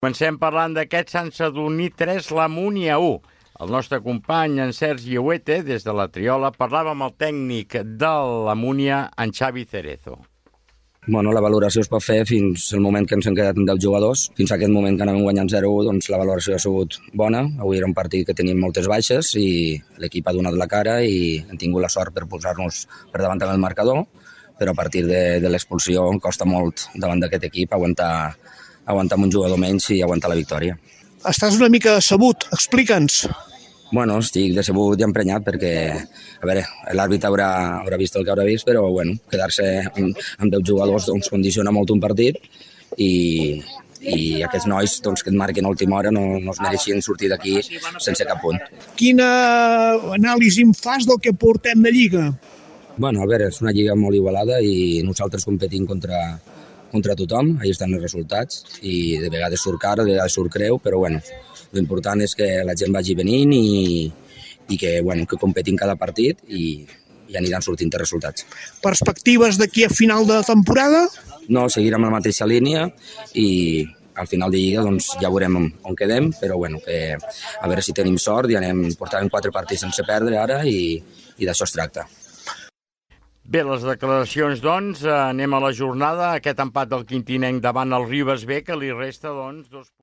Pública municipal
Esportiu
FM